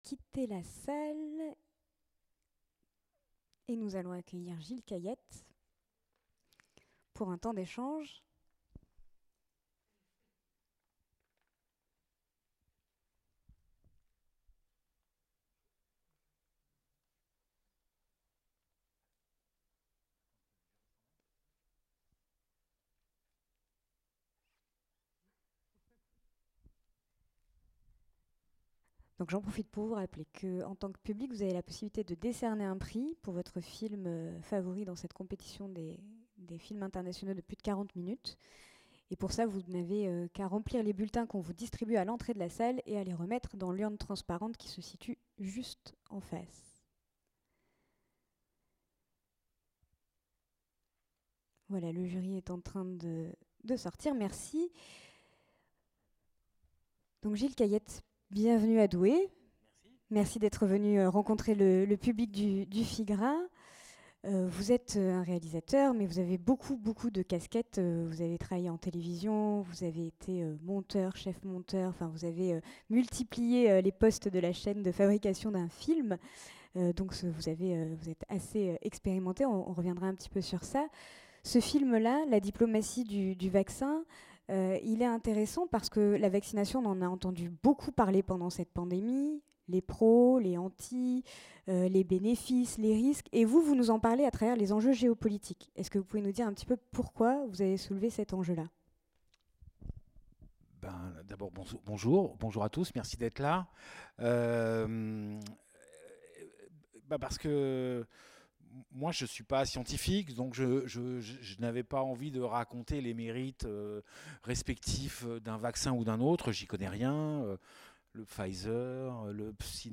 Écoutez ici une sélection des débats enregistrés après les projections.